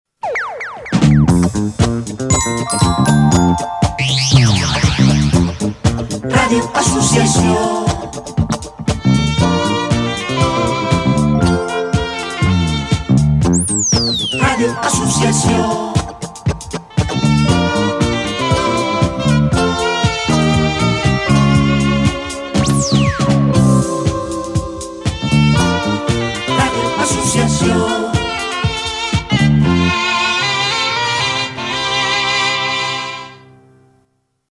Indicatiu llarg